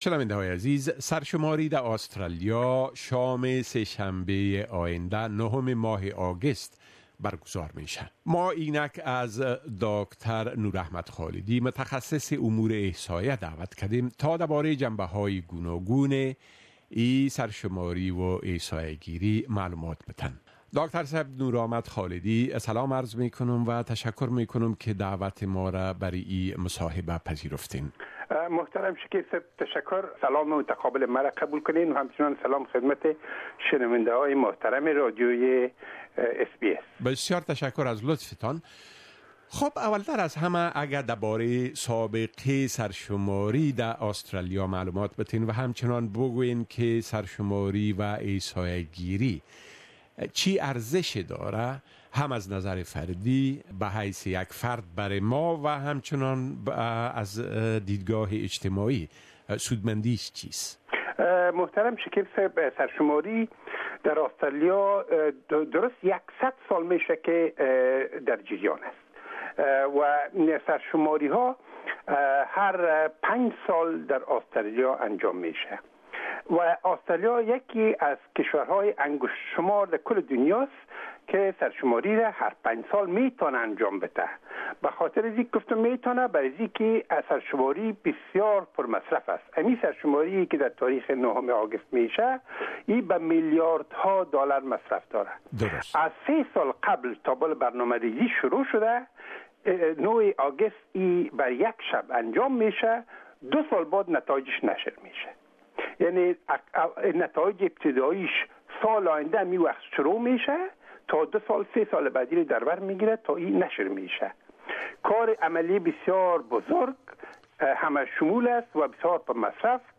متخصص امور احصائيه در مصاحبۂ دربارۂ اهميت سرشماری كه شام نهم اگست در آسترليا برگزار ميشود، صحبت كرده